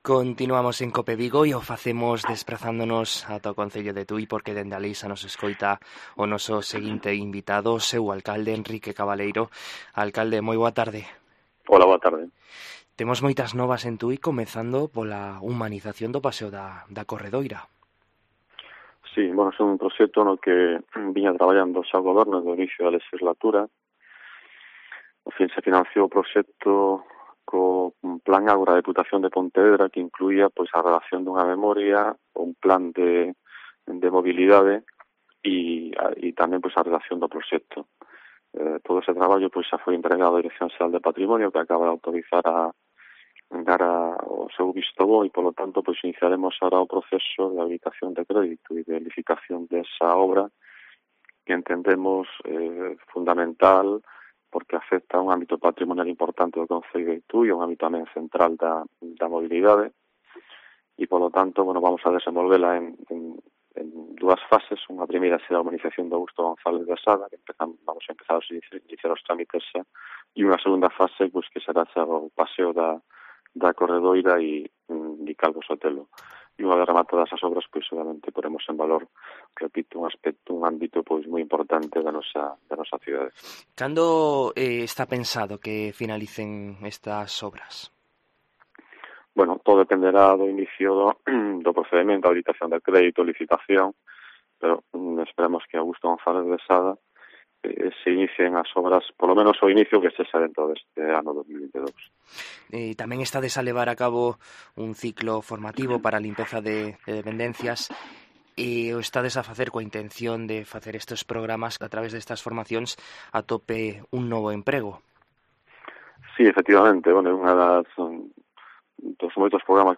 En COPE Vigo hablamos con el alcalde de Tui, Enrique Cabaleiro, para conocer la actualidad de este municipio del sur de la provincia de Pontevedra